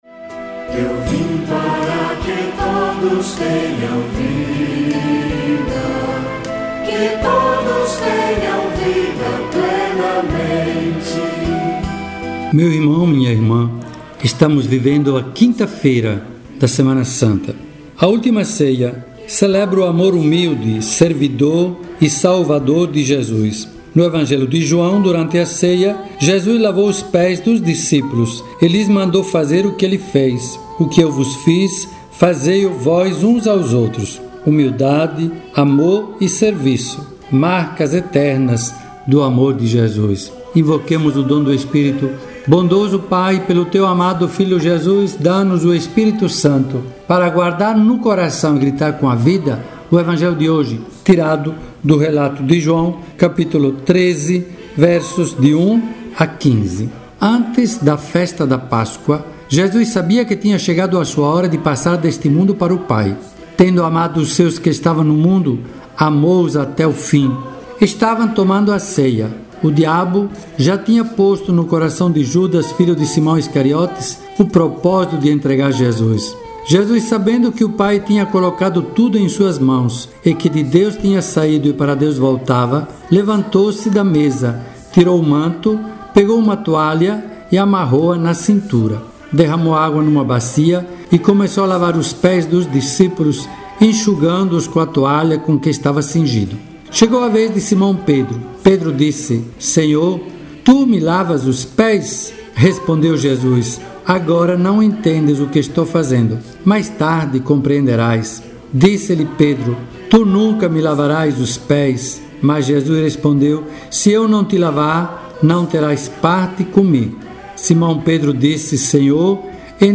Ouça abaixo a reflexão do dia com o bispo diocesano dom Egídio Bisol: